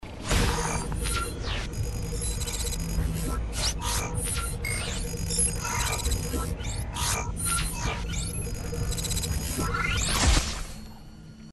Звуки хищника
На этой странице собраны звуки хищников: рычание, вой, крики и другие устрашающие аудио.